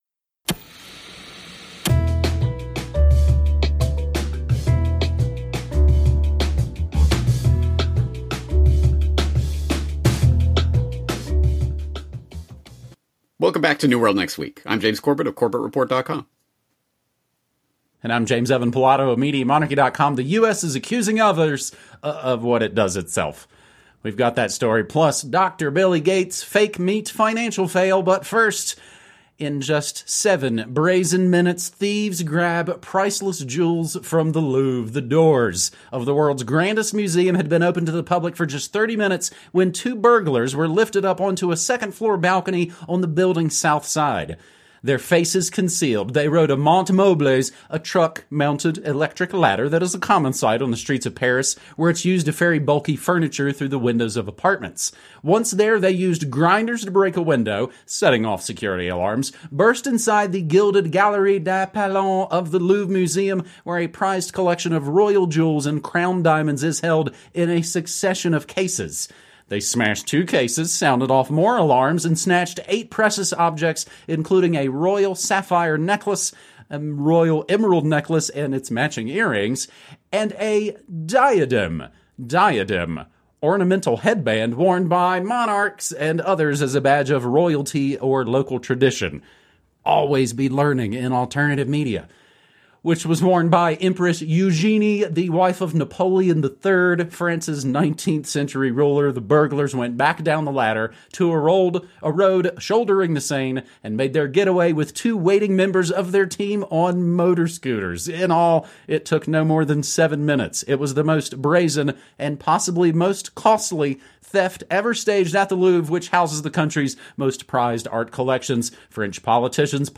Issues covered include 9/11 and false flag terror, the Big Brother police state, the global warming hoax and how central banks control the political process. Guests include politicians, scientists, activists and newsmakers from around the world.